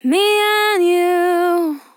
Me and you Vocal Sample
Categories: Vocals